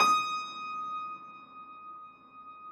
53g-pno19-D4.wav